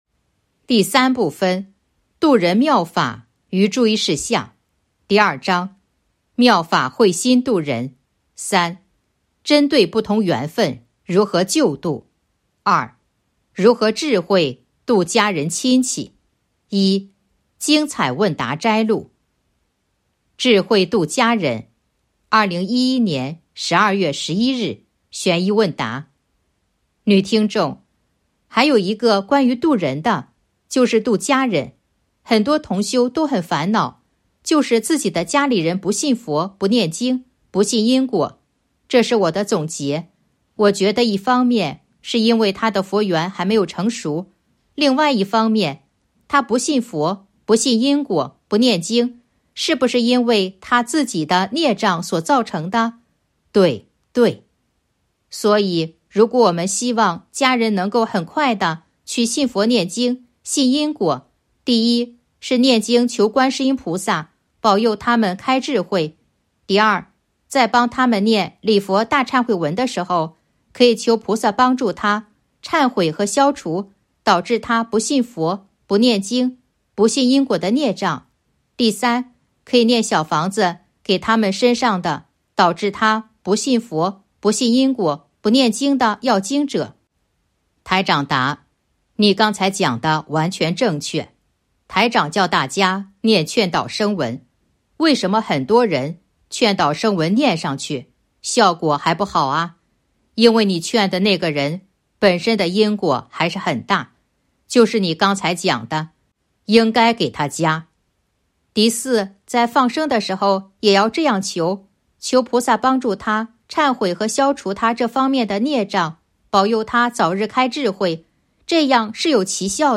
023.（二）如何智慧度家人亲戚 1. 精彩问答摘录《弘法度人手册》【有声书】 - 弘法度人手册 百花齐放